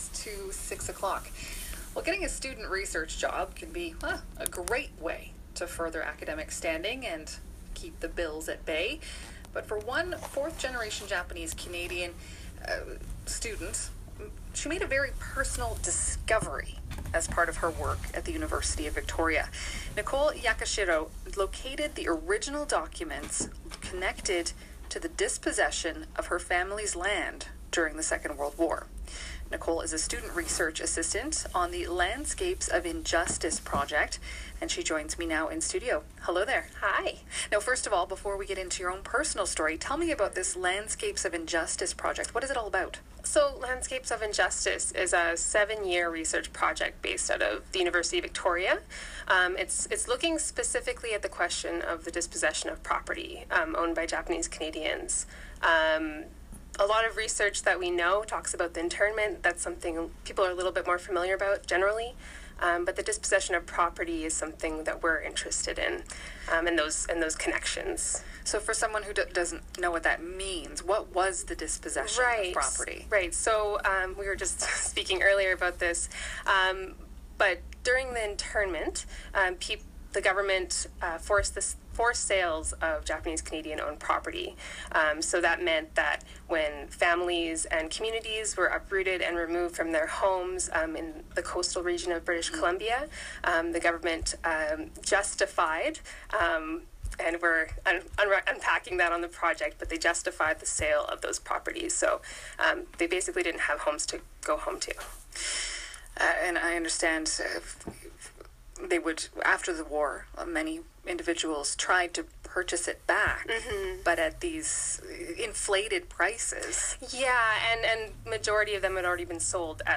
The radio interview was broadcast Thursday, April 27, on CBC Radio 90.5FM.